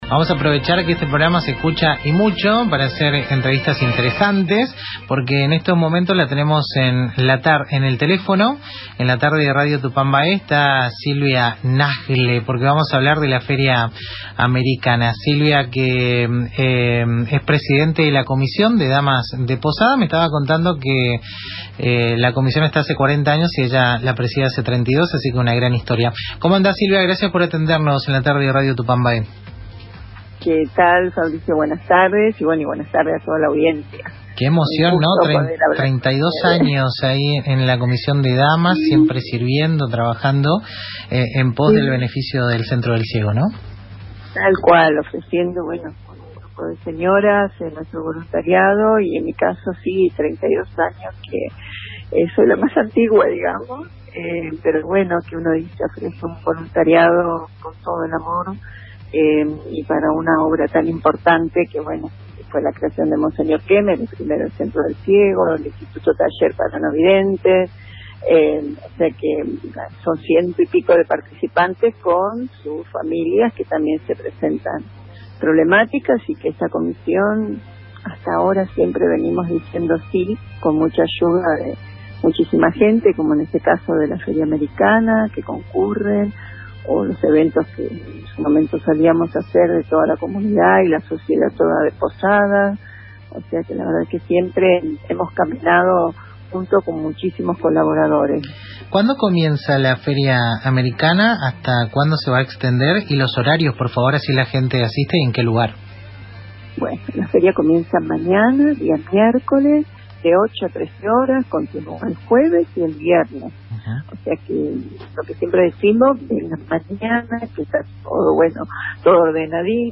BannerPrincipal Entrevistas